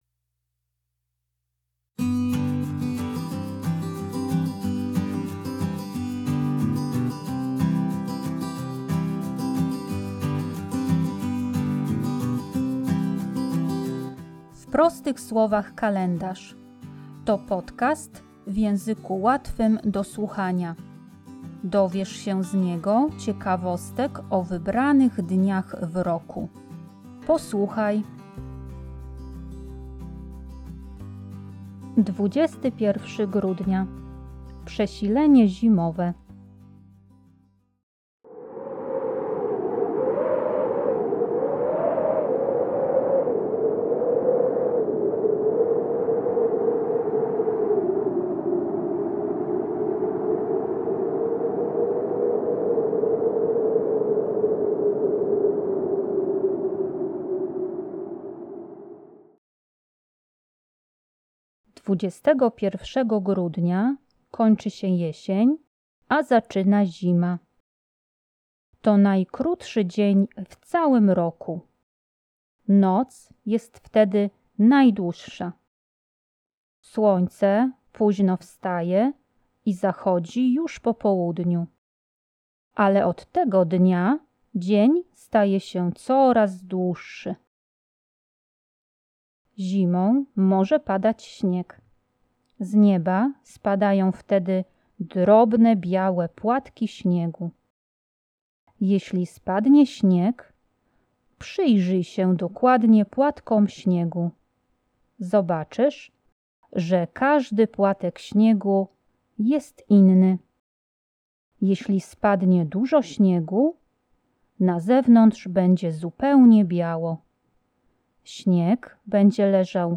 W podcaście usłyszycie szum zimowego wiatru.